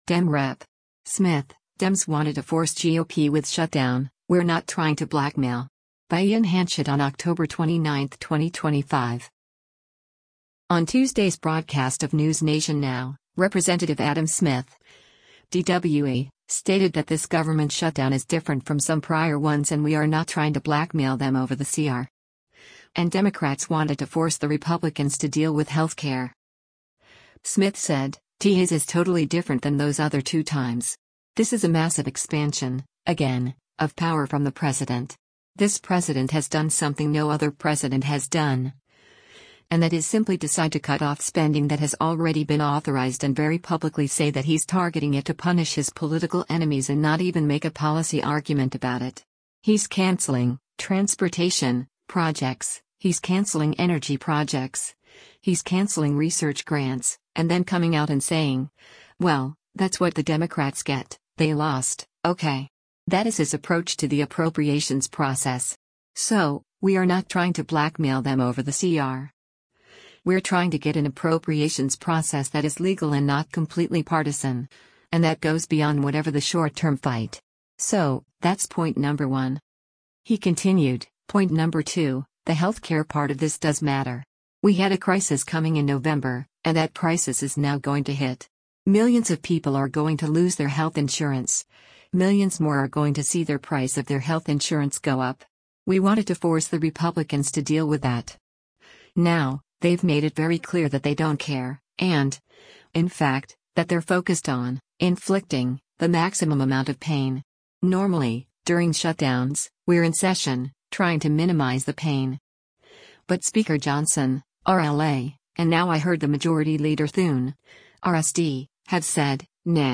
On Tuesday’s broadcast of “NewsNation Now,” Rep. Adam Smith (D-WA) stated that this government shutdown is different from some prior ones and “we are not trying to blackmail them over the CR.” And Democrats “wanted to force the Republicans to deal with” health care.